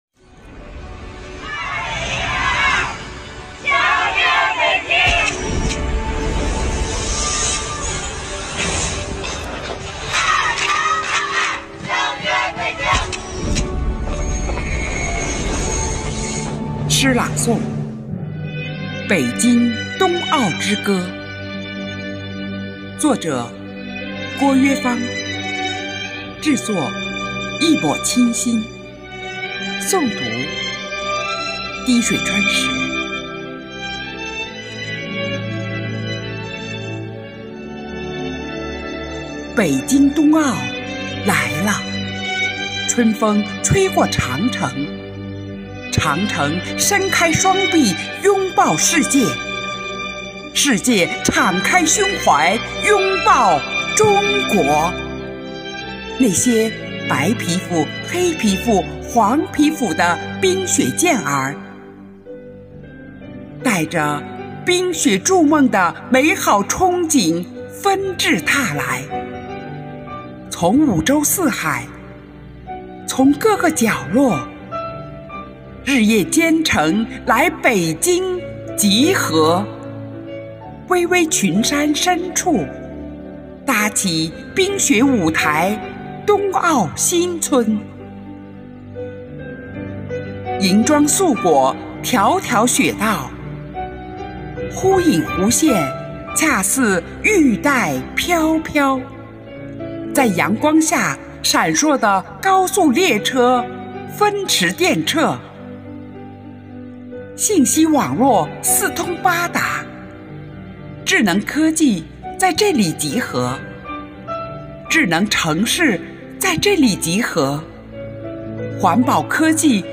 以诗为言，以声传情，用诗文朗诵抒发了对过去一整年的留恋与不舍以及对新年的憧憬与期盼，真情展现了生活好课堂朗读志愿者的朗读风采，队员们彼此感情更加深切。
生活好课堂幸福志愿者八里庄西里朗读服务（支）队